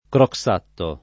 Croxatto [ krok S# tto ]